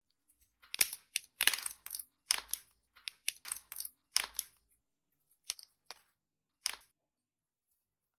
Tiếng bóc Siu, xé Seal, khui tem, đập hộp…
Thể loại: Tiếng động
Description: Tiếng bóc seal, xé seal, khui tem, đập hộp iPhone, âm thanh "crack", mở hộp đồ mới mua... giòn tan khi lớp seal bị tách ra thực sự gây nghiện và kích thích thính giác. Cảm giác rùng mình nhẹ khi nghe tiếng nilon bị kéo căng, lớp dán bung ra, mang đến vibe cực đã và thỏa mãn, như một ASMR unboxing chân thực... cho video đập hộp, edit clip công nghệ, mang lại trải nghiệm sống động, cuốn hút từng giây.
tieng-boc-siu-xe-seal-khui-tem-dap-hop-www_tiengdong_com.mp3